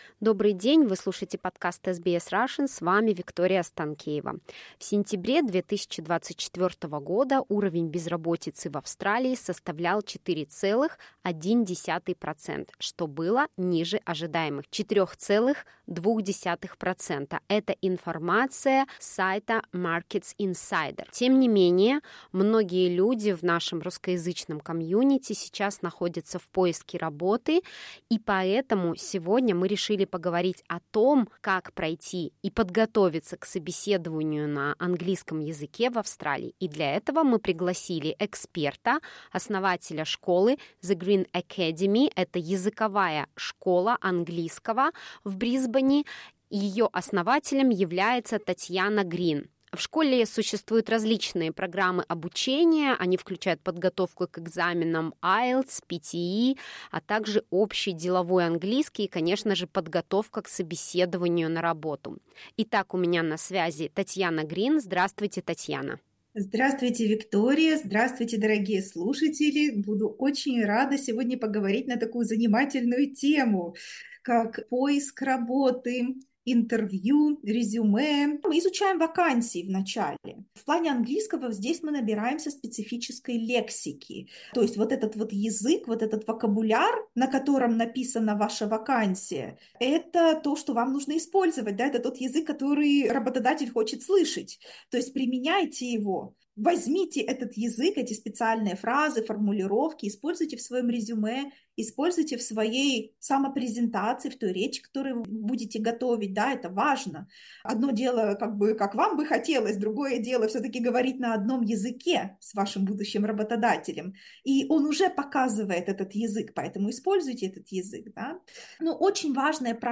How to prepare for an interview: Interview with the head of an English language school